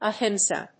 /əˈhɪmsɑː(米国英語)/